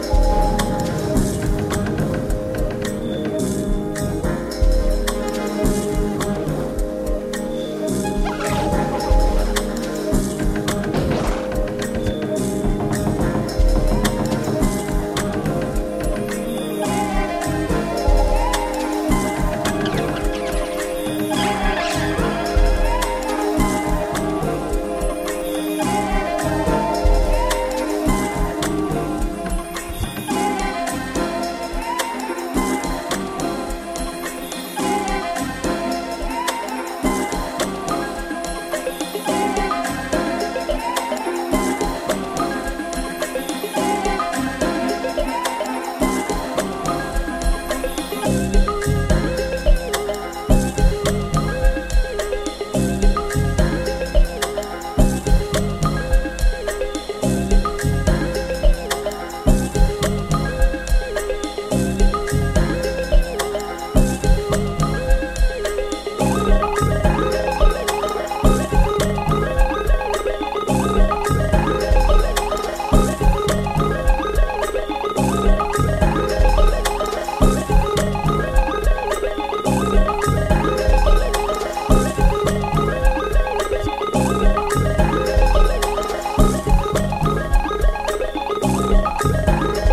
ambient, melodic techno and beach party ready house
Edinburgh-based duo
House Ambient